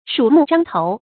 發音讀音
成語簡拼 smzt 成語注音 ㄕㄨˇ ㄇㄨˋ ㄓㄤ ㄊㄡˊ 成語拼音 shǔ mù zhāng tóu 發音讀音 常用程度 一般成語 感情色彩 貶義成語 成語用法 作賓語、定語；用于比喻句 成語結構 聯合式成語 產生年代 古代成語 近義詞 獐頭鼠目 成語例子 〖示例〗我 鼠目獐頭 ，強似他面如傅粉。